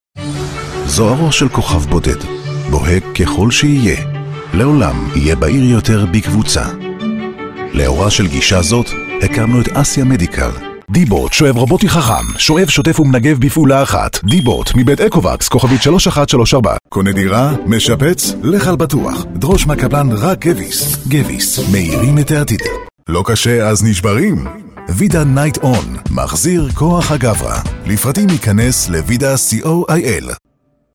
Native speaker Male 30-50 lat
Narracja
Demo lektorskie